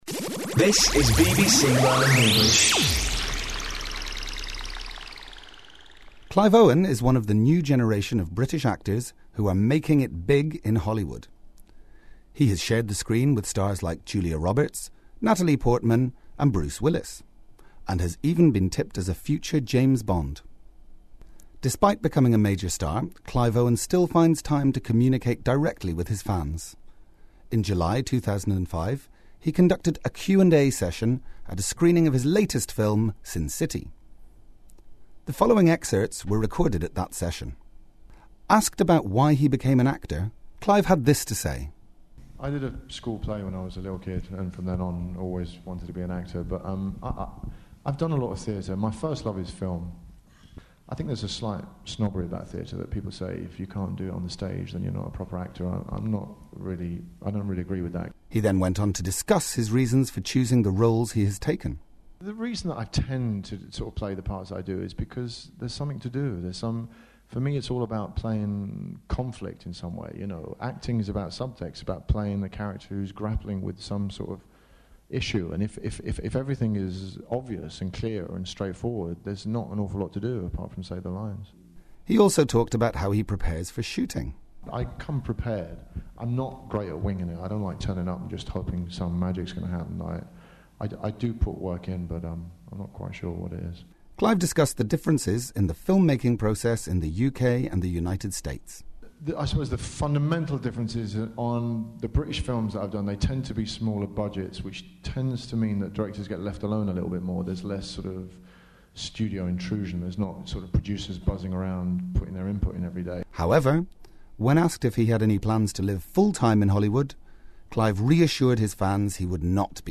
In July 2005 he conducted a Q and A session at a screening of his latest film, Sin City. The following excerpts were recorded at that session.